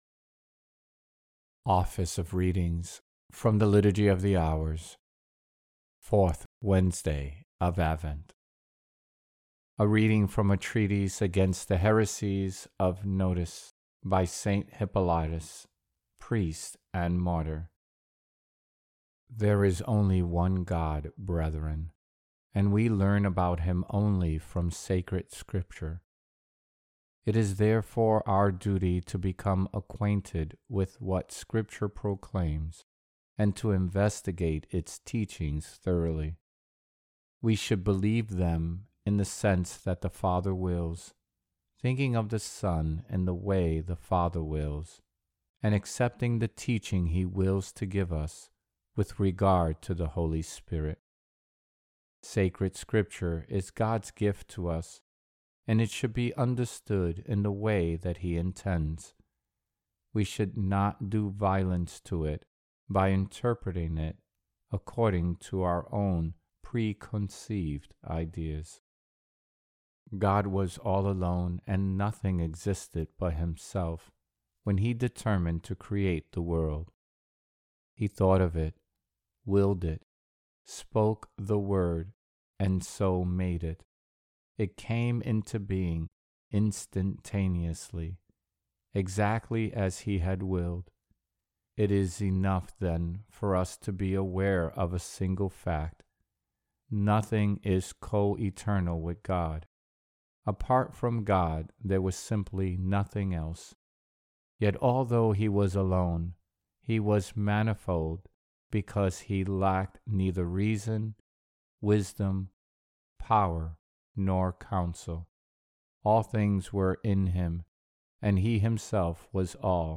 Fourth Wednesday in Advent